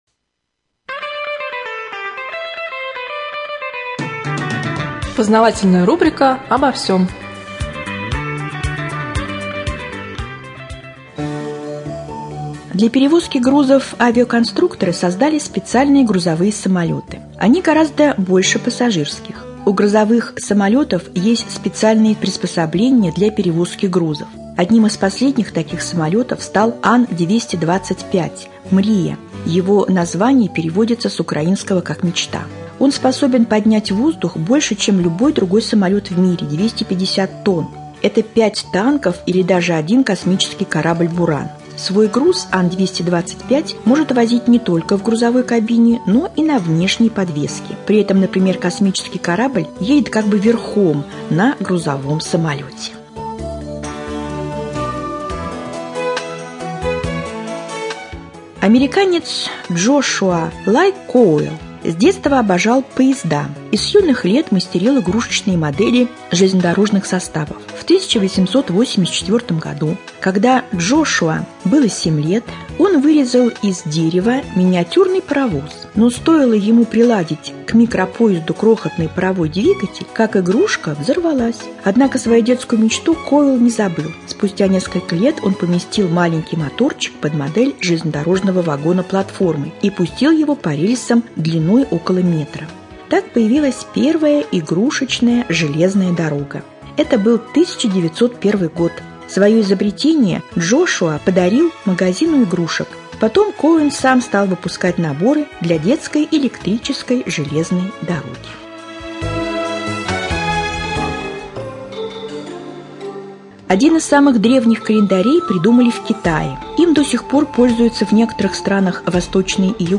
Прямой эфир с заместителем председателя Совета депутатов Раменского муниципального района Антон Евгеньевич Попов, обо всем.